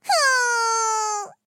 公羊2小破语音2.OGG